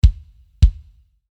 Bassdrum-Tuning: Pop/Rock/Fusion-Sound
Dieser zeichnet sich im Wesentlichen durch einen satten Attack und ein tiefes, aber kurzes Sustain aus.
Dadurch erzielt man eine schnelle Überblendung von der Attack-Phase in die Sustain-Phase.